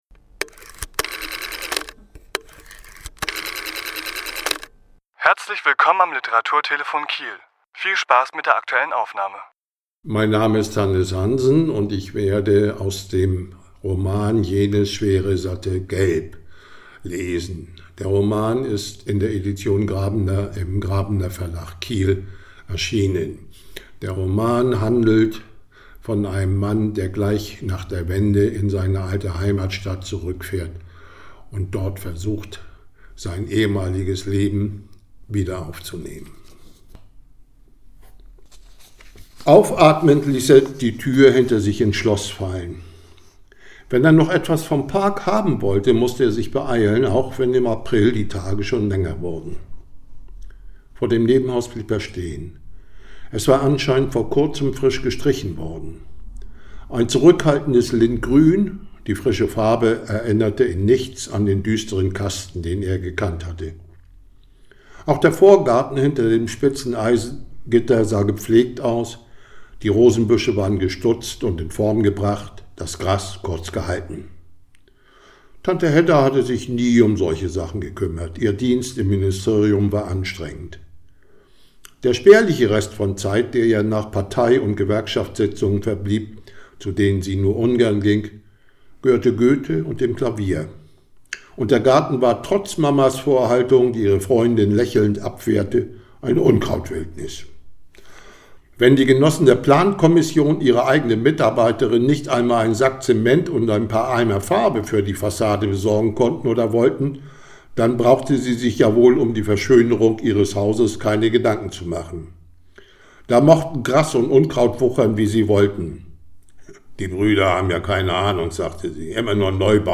Autor*innen lesen aus ihren Werken